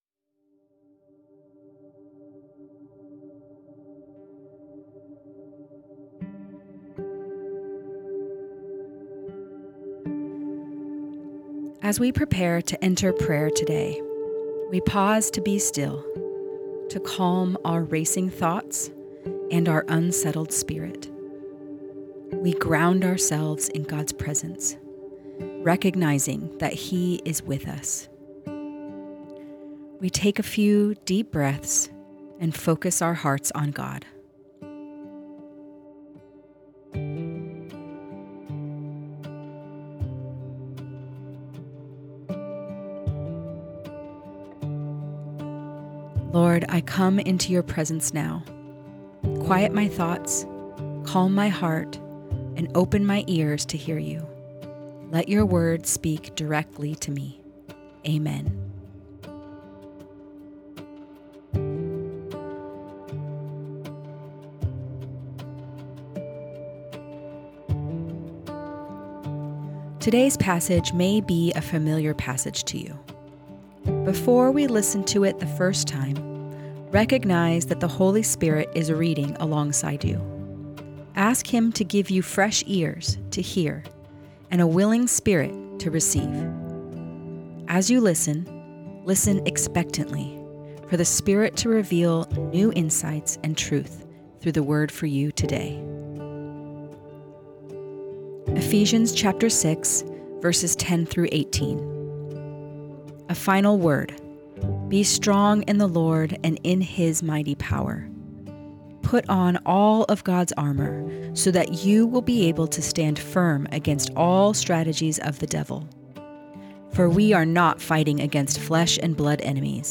Guided Listening Practice Prepare As we prepare to enter prayer today, we pause to be still, to calm our racing thoughts and our unsettled spirit.